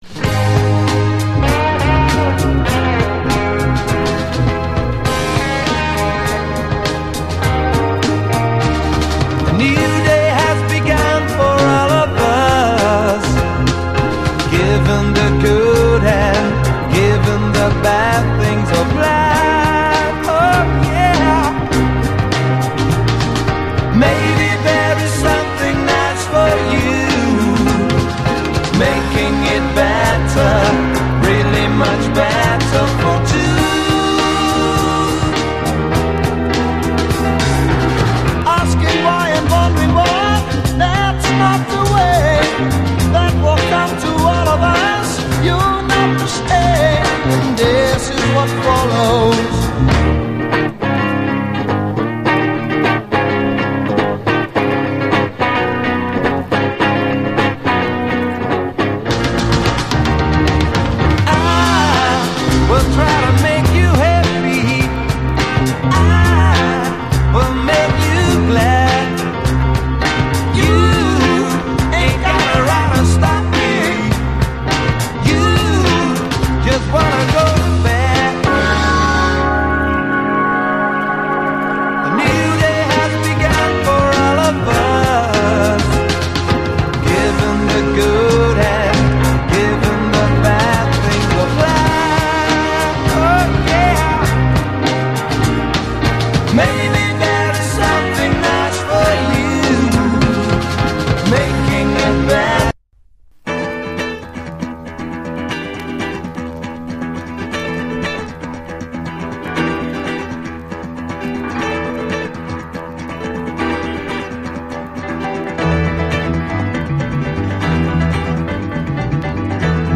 ファズ・ギターと共に砂埃と青春のエネルギーも刻み込んだ、イナタくもスリリングな名演メキシカン・ガレージ揃い